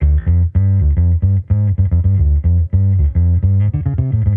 Index of /musicradar/sampled-funk-soul-samples/110bpm/Bass
SSF_PBassProc2_110D.wav